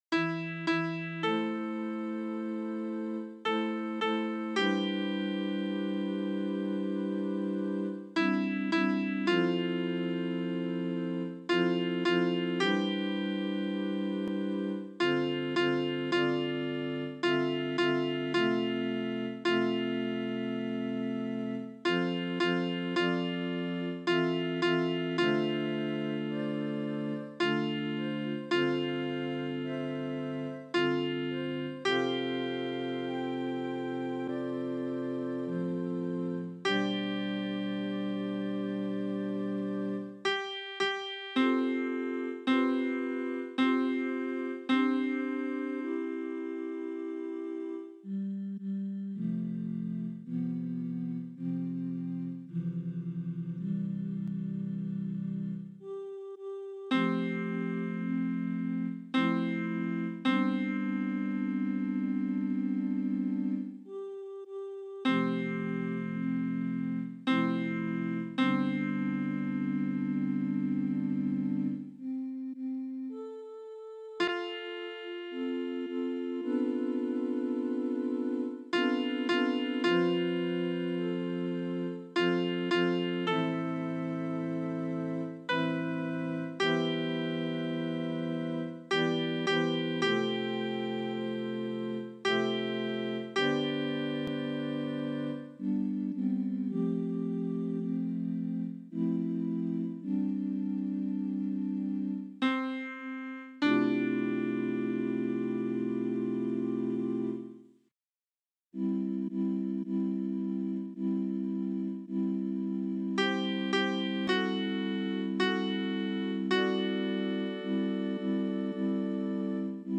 Versions piano
ALTO 2